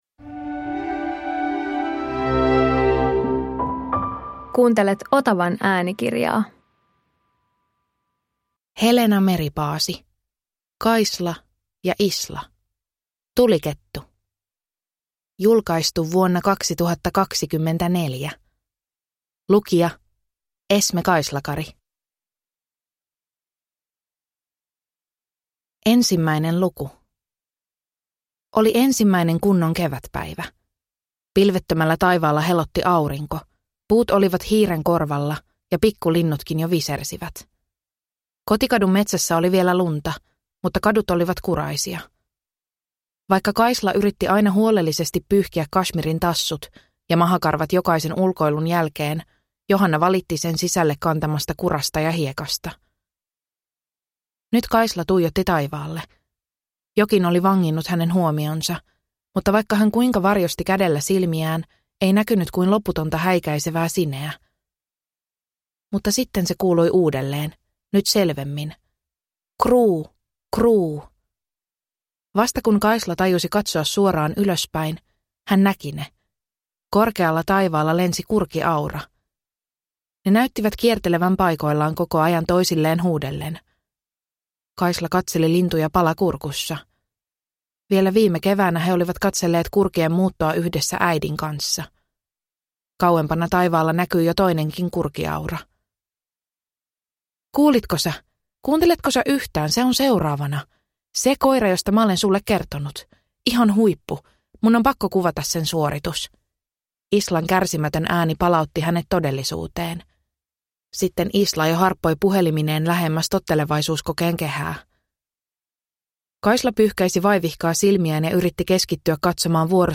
Kaisla ja Isla - Tulikettu – Ljudbok